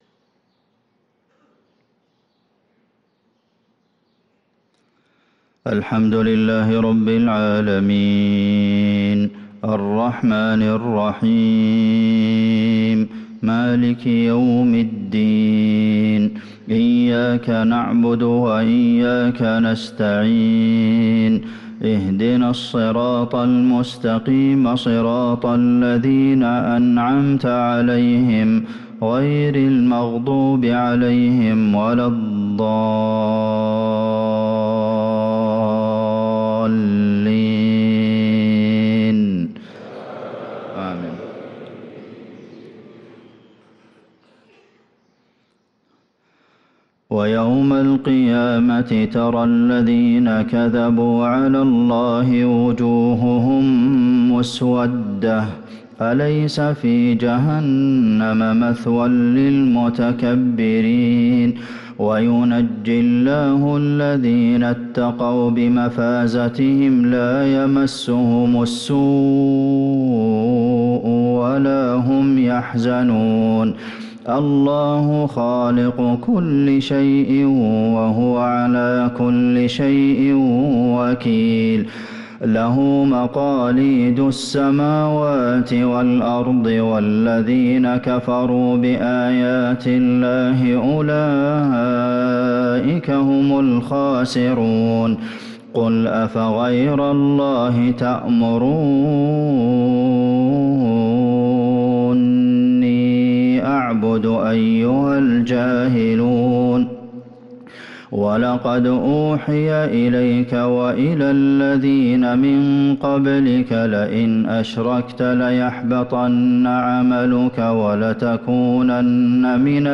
صلاة الفجر للقارئ عبدالمحسن القاسم 25 جمادي الأول 1445 هـ
تِلَاوَات الْحَرَمَيْن .